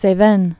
(sā-vĕn)